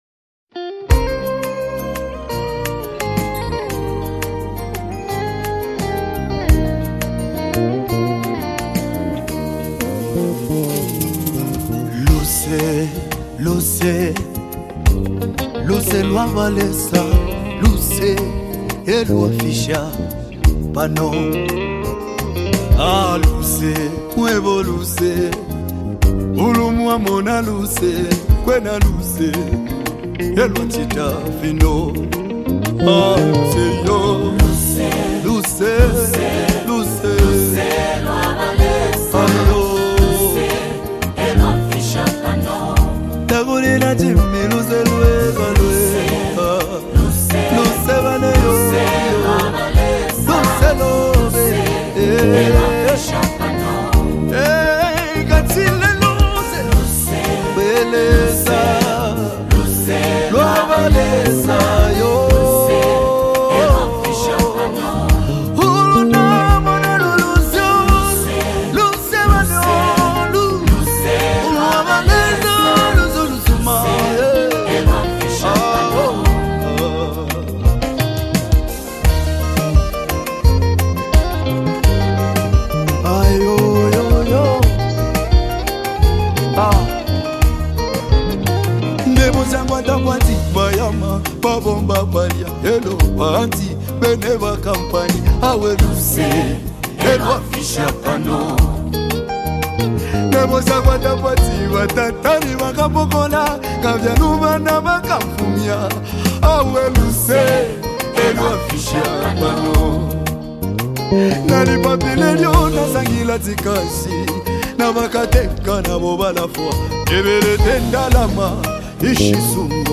heartwarming gospel song